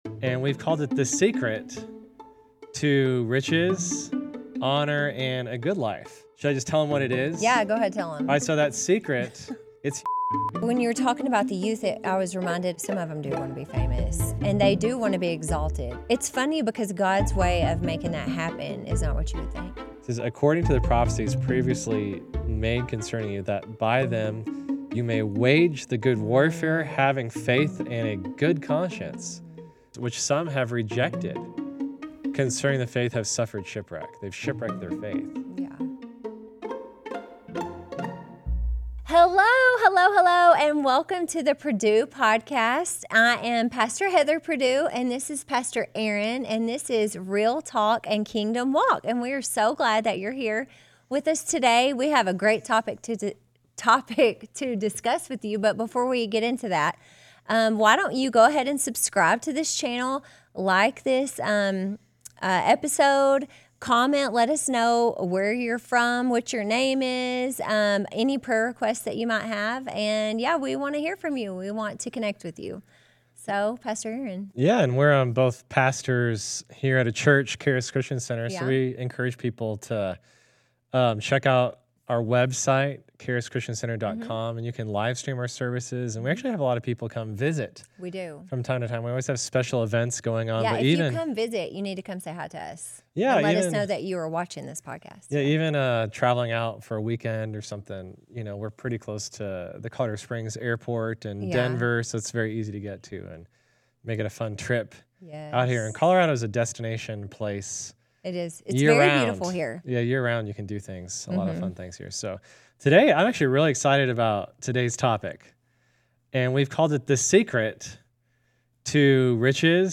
The pastors talk about real-life examples, like how pride started with Lucifer and how it ruins relationships today.